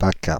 Ääntäminen
Ääntäminen Paris: IPA: [pa.ka] France (Paris): IPA: [pa.ka] Haettu sana löytyi näillä lähdekielillä: ranska Käännöksiä ei löytynyt valitulle kohdekielelle.